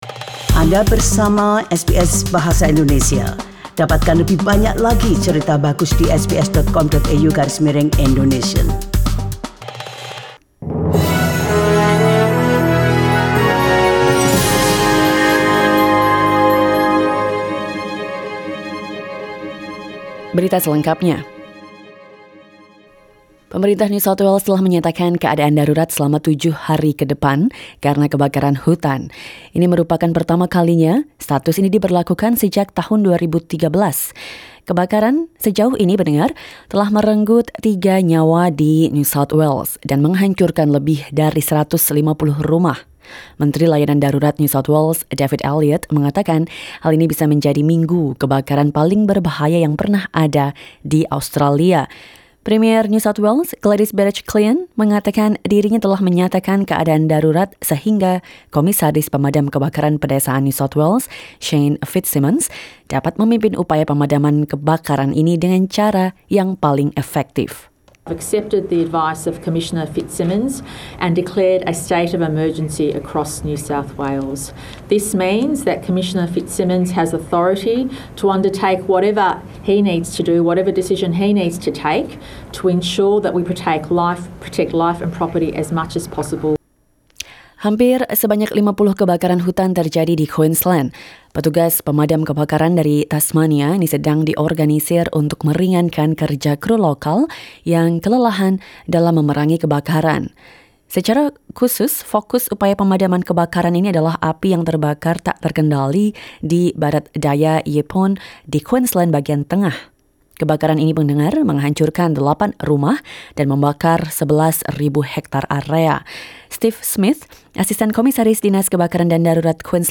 SBS Radio news in Indonesian - 11 November 2019